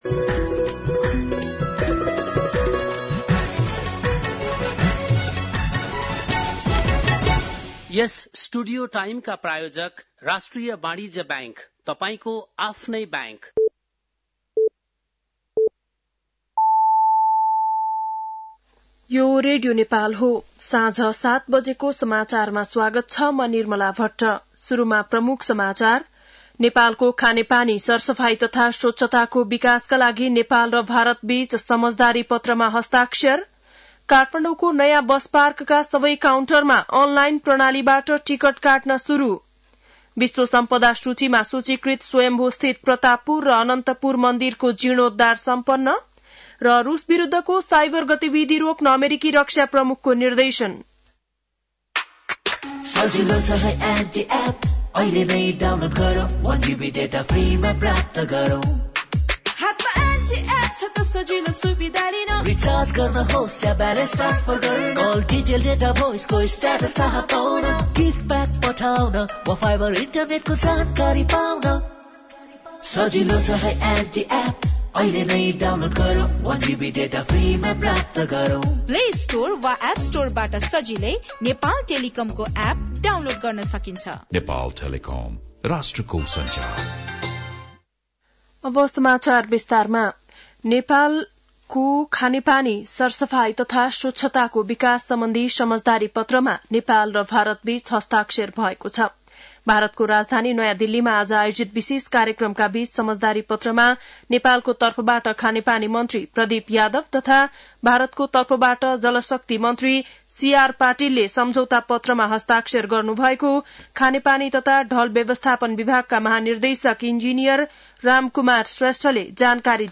बेलुकी ७ बजेको नेपाली समाचार : २० फागुन , २०८१
7-PM-Nepali-NEWS-11-19.mp3